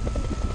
TB_propeller.ogg